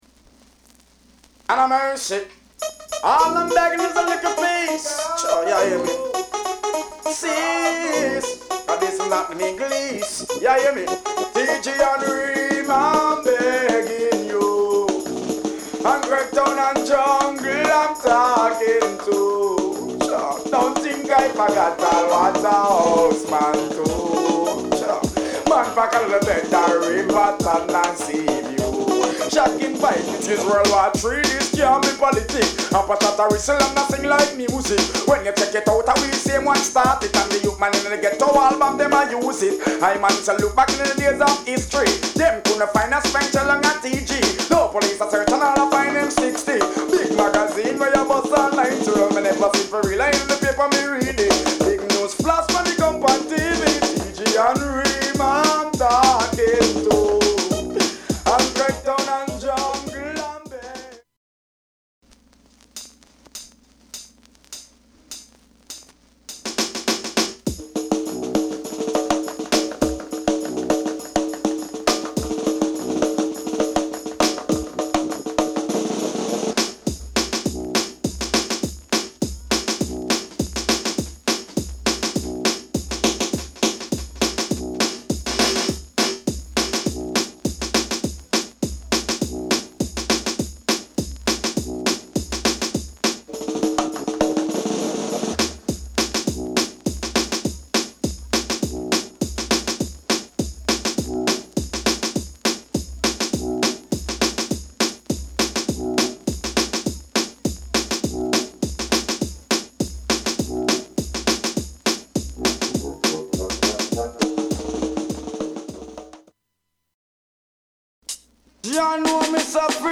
REGGAE / DANCEHALL
プレス・ノイズ有り（JA盤、Reggaeのプロダクション特性とご理解お願い致します）。